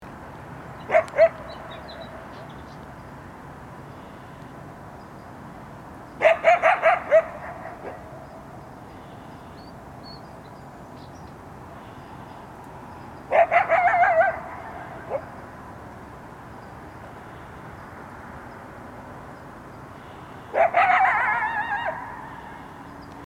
This is the last 1/2 minute of a 5+ minute howling session. You can hear *her* faintly in the distance at the end.
The day “for us” began with me finding him in his park howling in response to a siren as dawn broke. His mate responded from far, far off — barely audible, but distinctly her response.